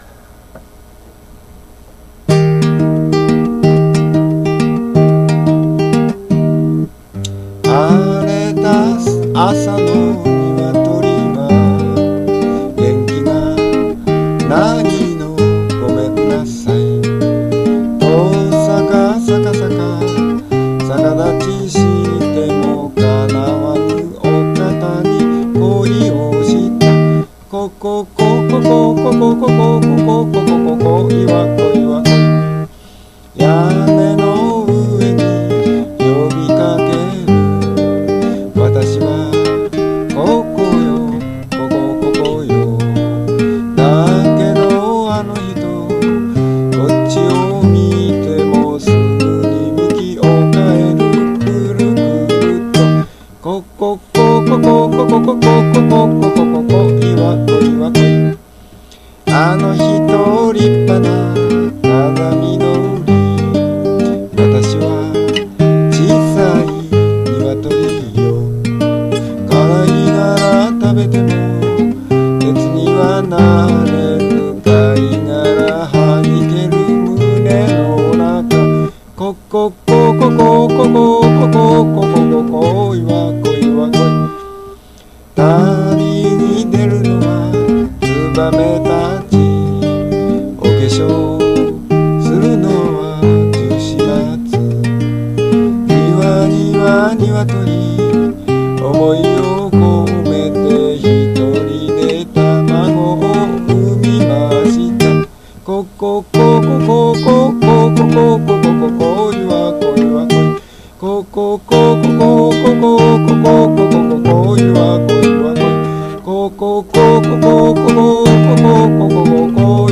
F　（Capo:5 Play:C) T=120